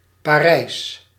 Ääntäminen
Ääntäminen Anglicised: IPA : /ˈpæɹ.ɪs/ US : IPA : [ˈpɛɹ.ɪs] French: IPA : /pɑˈri/ Haettu sana löytyi näillä lähdekielillä: englanti Käännös Ääninäyte Erisnimet 1.